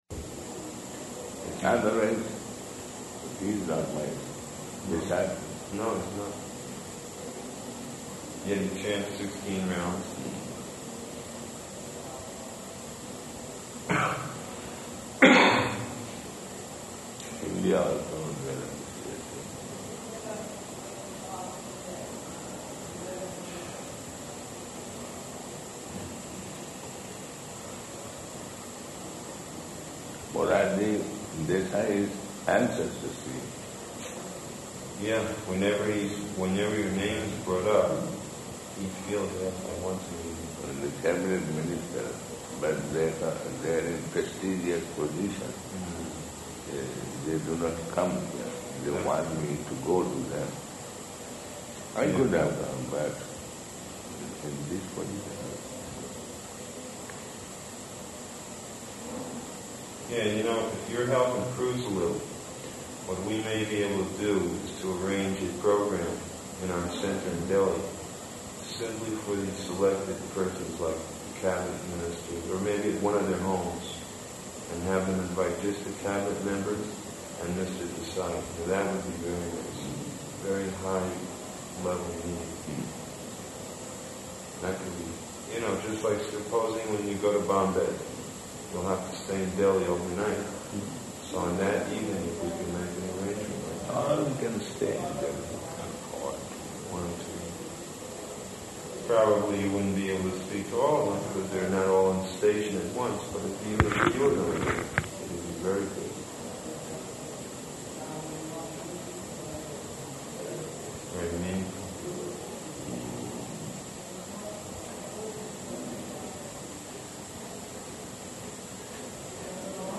-- Type: Conversation Dated: July 2nd 1977 Location: Vṛndāvana Audio file